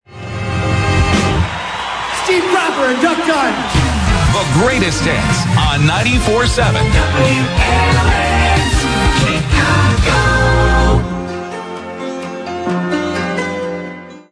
WLS-FM Top of the Hour Audio: